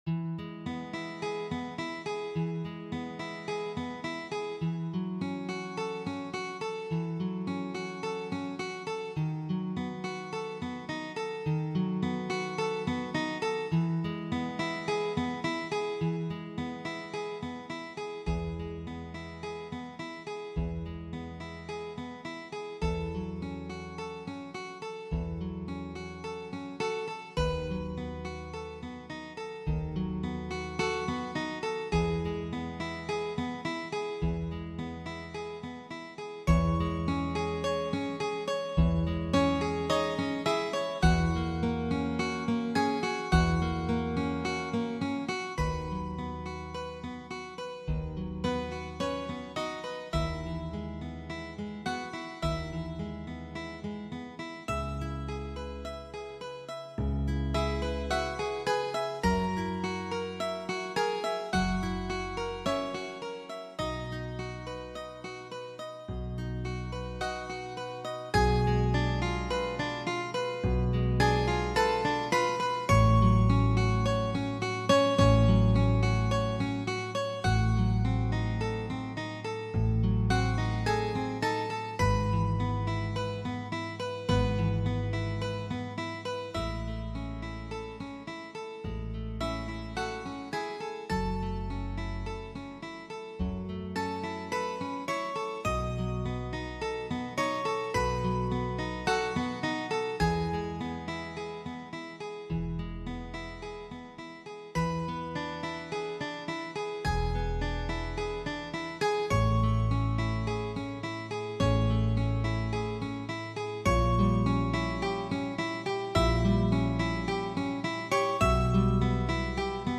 Orchestration: Zupforchester